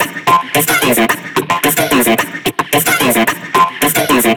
这款采样包包含 62 个极具感染力的人声采样，是您为音乐注入原始能量的理想之选。